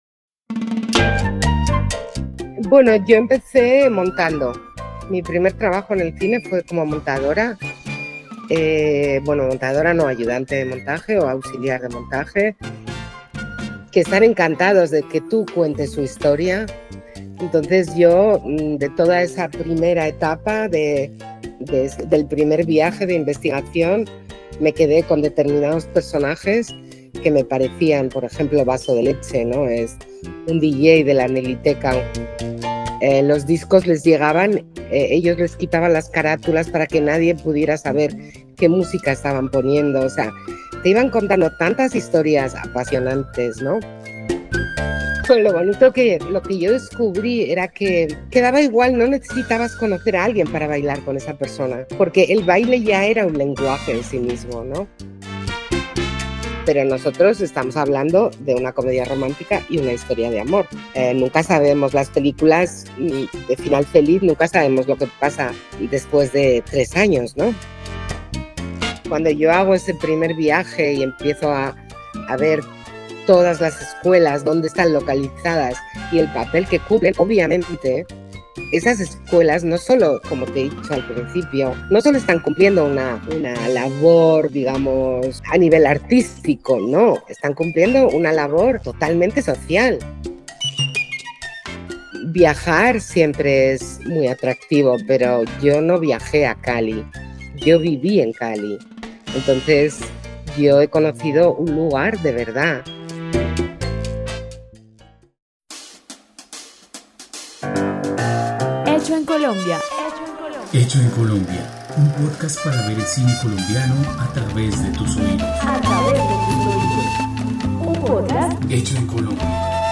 ..Pódcast. Escucha ahora a Chus Gutiérrez, directora de la comedia romántica Ciudad delirio, en Hecho en Colombia por la plataforma de streaming RTVCPlay.